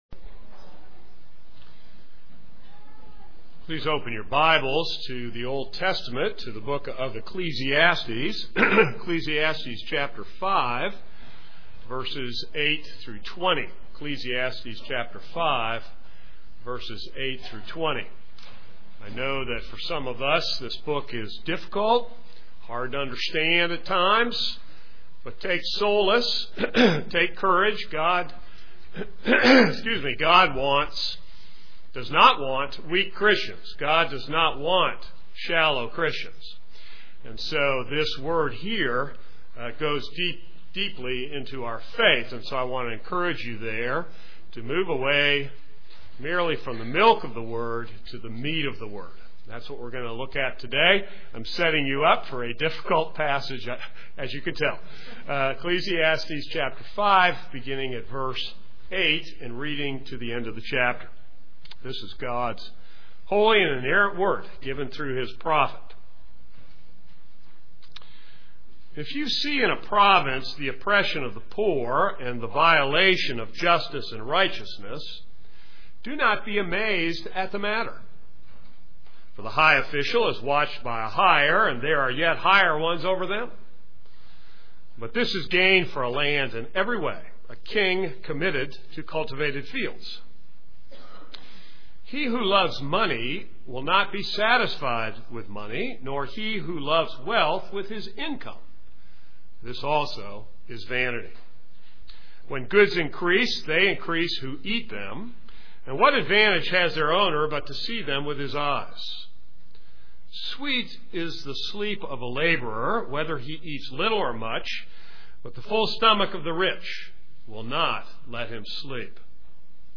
This is a sermon on Ecclesiastes 5:8-20.